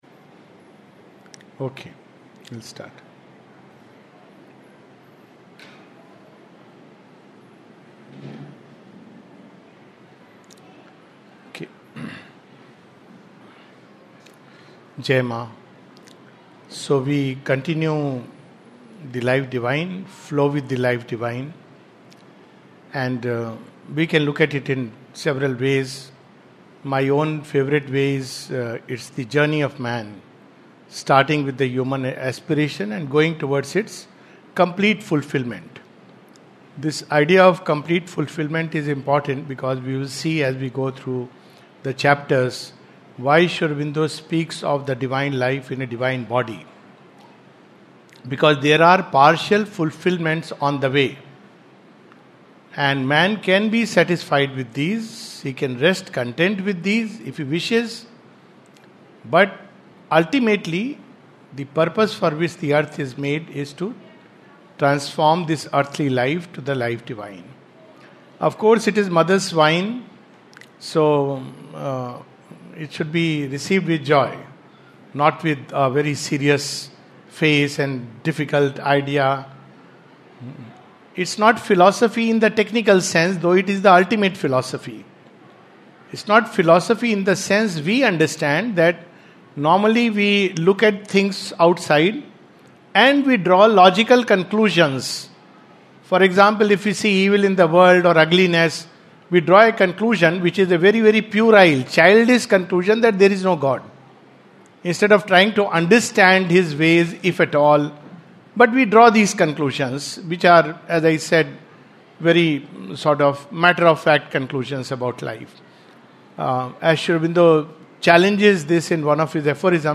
The Life Divine, Session # 04-01 at Sri Aurobindo Society, Pondicherry - 605002, India We quickly make a summary note of what has been covered so far.